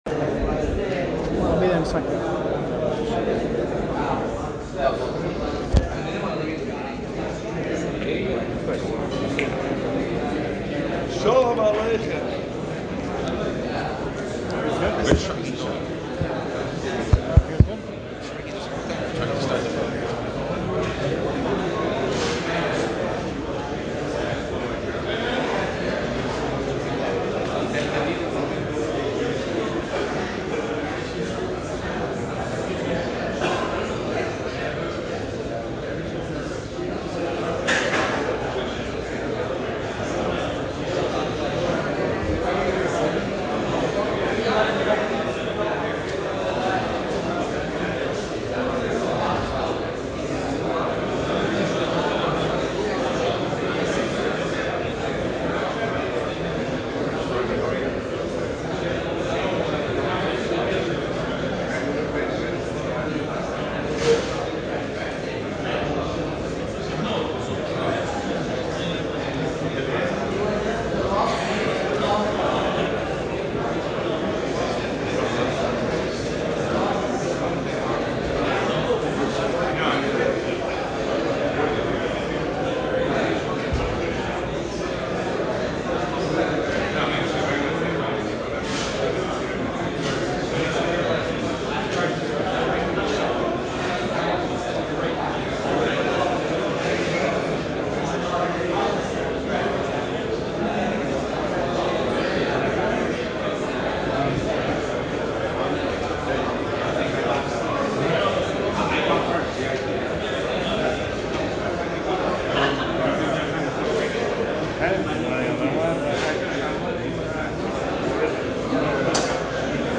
On Sunday July 24th the Yeshiva held its annual alumni event in the Los Angeles area. Over one hundred talmidim and friends of the Yeshiva came out for an evening of Torah and chizzuk.